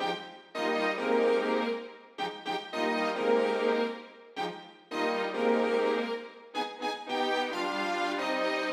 13 Strings PT 3-4.wav